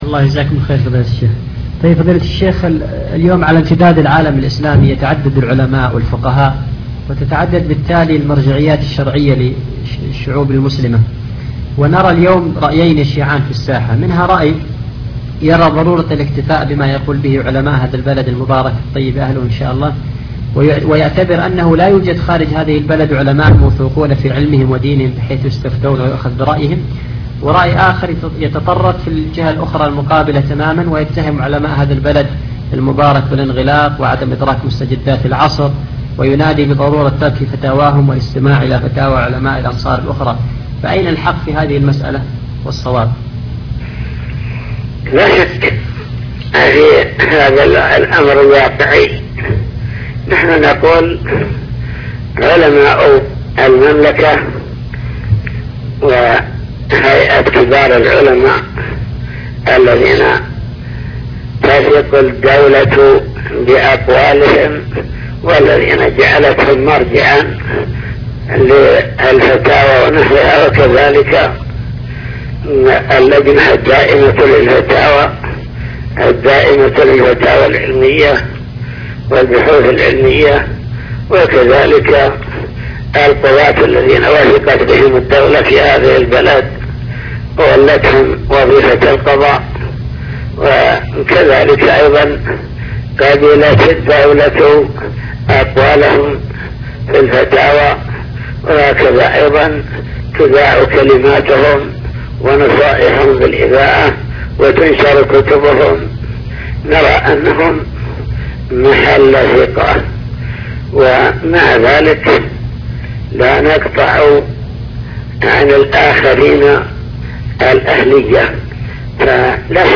تسجيلات - لقاءات